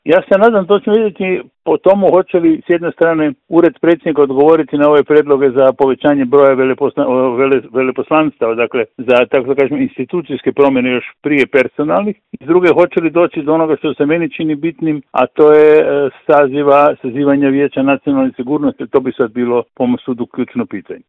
Razgovor je za Media servis komentirao politički analitičar Žarko Puhovski: